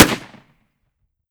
30-30 Lever Action Rifle - Gunshot A 004.wav